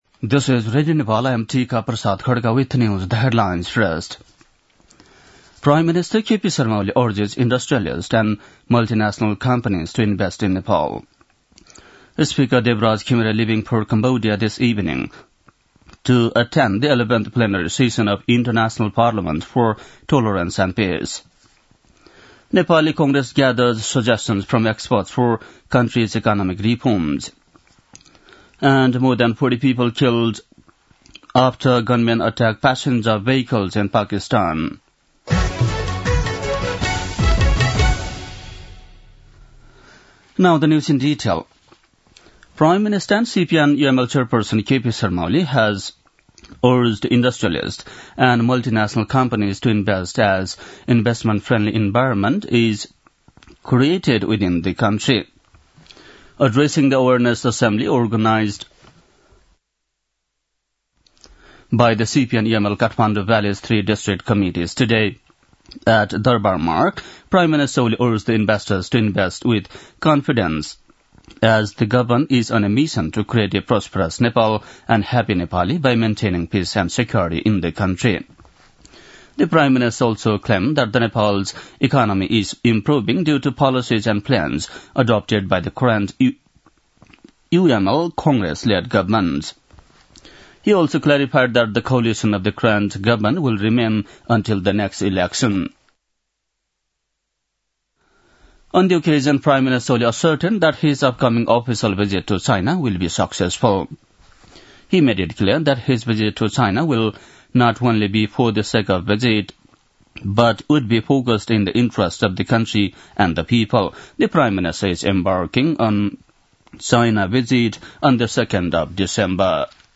बेलुकी ८ बजेको अङ्ग्रेजी समाचार : ८ मंसिर , २०८१
8-pm-english-news-8-07.mp3